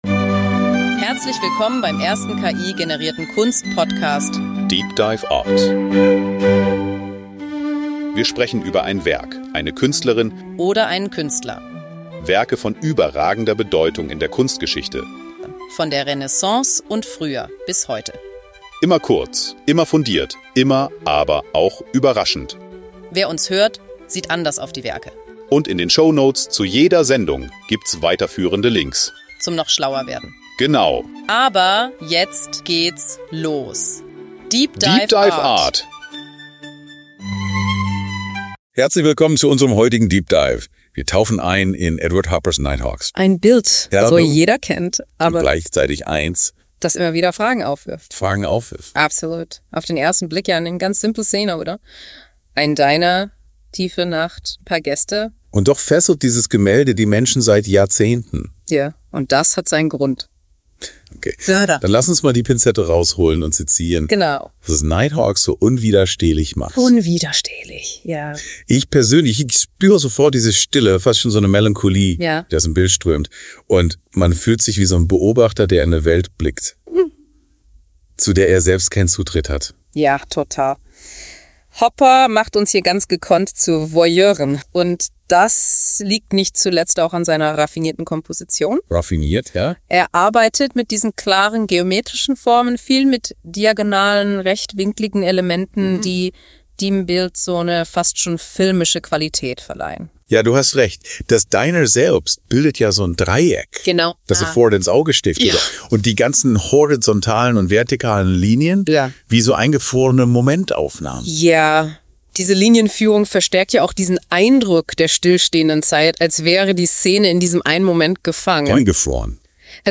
Unser Duo reflektiert über Hoppers Leben, seinen Einfluss auf die Kunst und die Bedeutung des Titels.